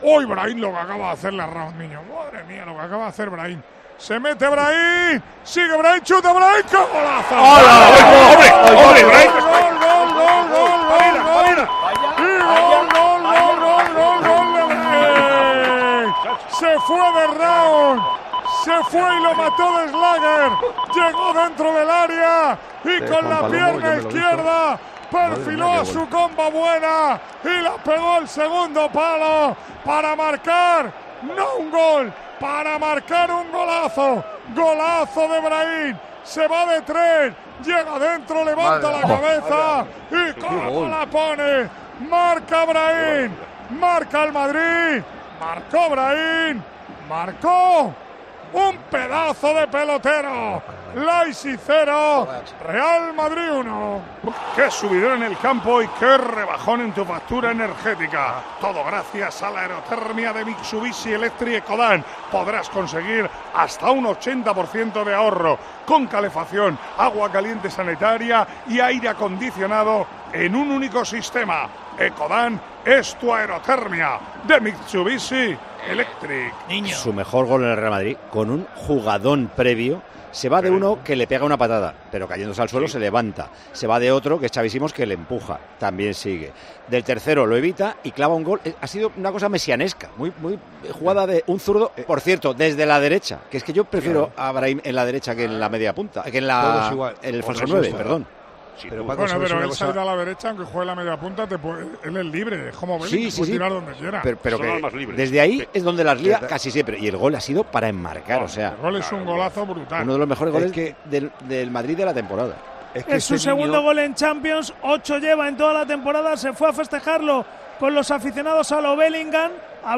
ESCUCHA EN DIRECTO EN TIEMPO DE JUEGO EL LEIPZIG - REAL MADRID, IDA DE LOS OCTAVOS DE FINAL DE LA LIGA DE CAMPEONES